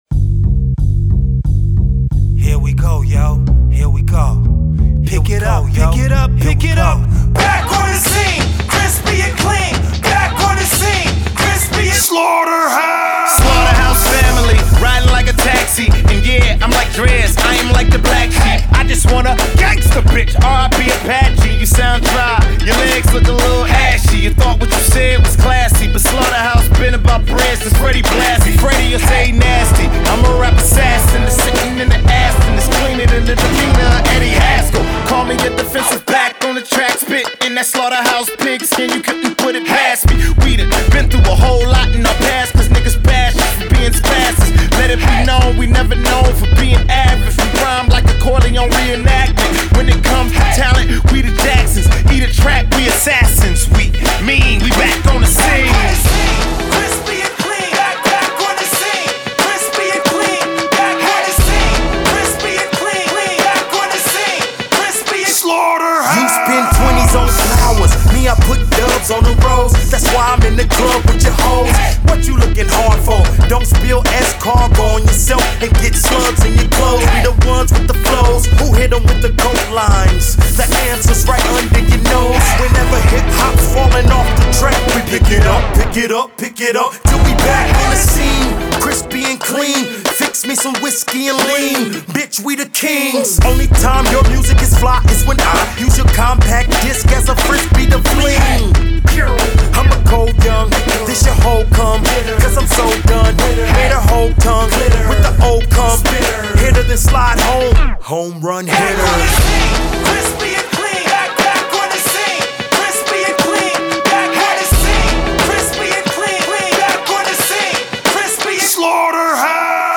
screaming, shortened chorus